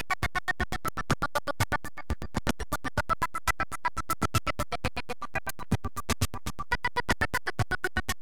mixxx-headphone-choppiness-23.2ms-buffer.ogg